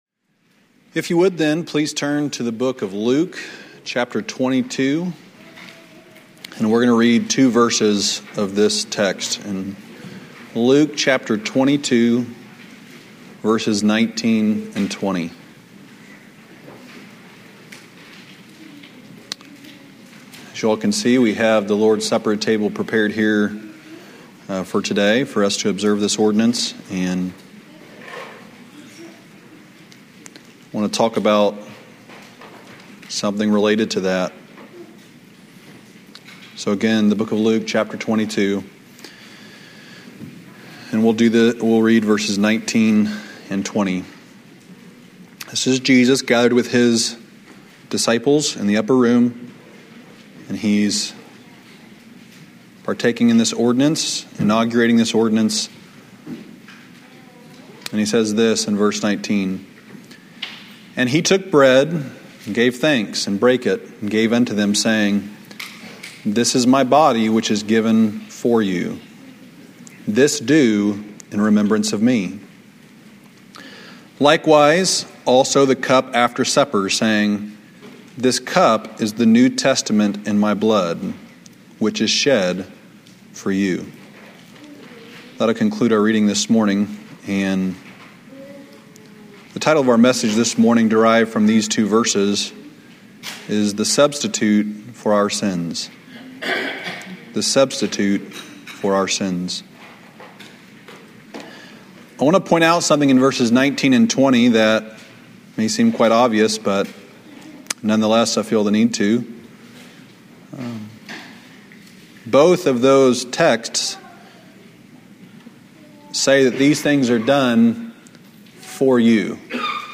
2012 Old Union Ministers School Day 2 Devotional